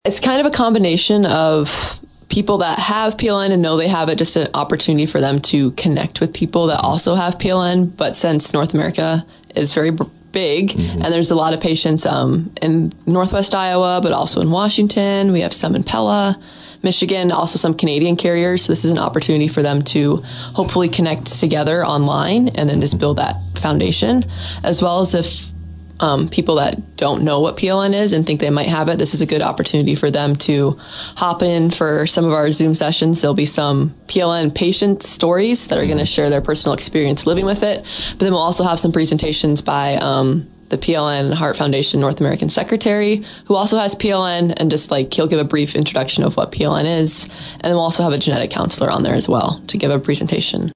Some of the audio in this story comes from our fellow Community First Broadcasting station, KSOU’s program, “The Daily Grind.”